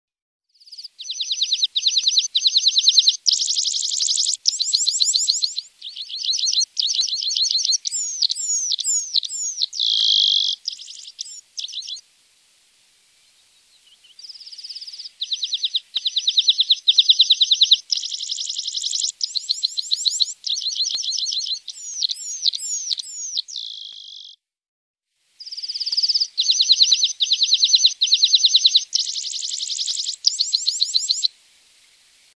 Red-throated Pipit
Red_throated_Pipit.mp3